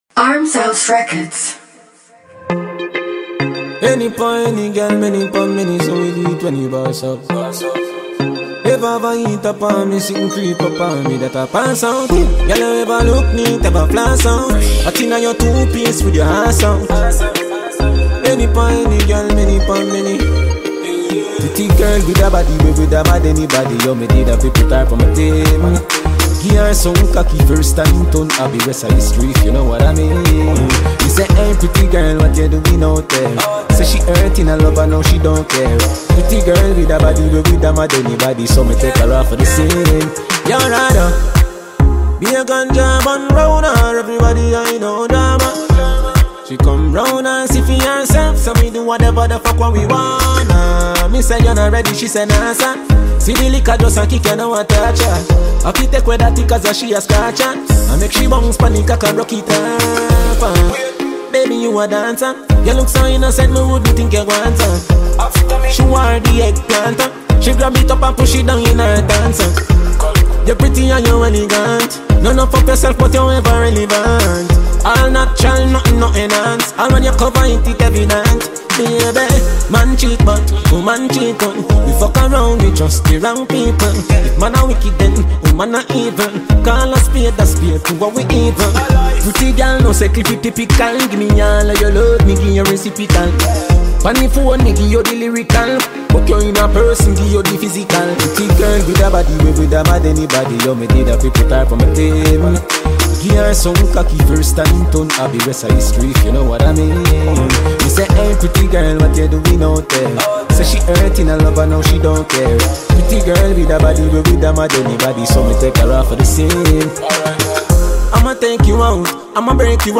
Reggae/Dancehall
For the dancehall lovers.